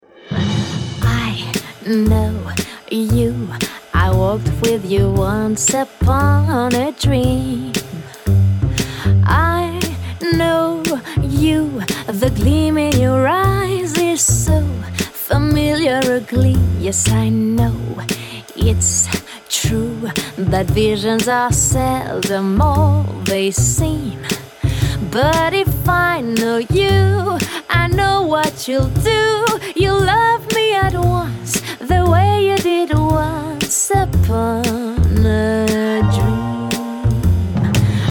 Foxtrot IV